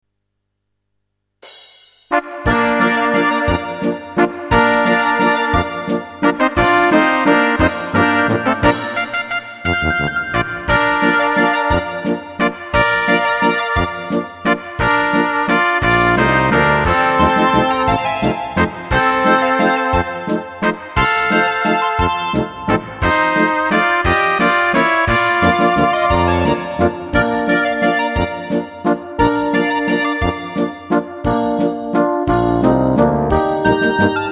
Rubrika: Národní, lidové, dechovka
- valčík
Karaoke